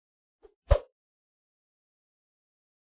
meleeattack-swoosh-light-group06-00.ogg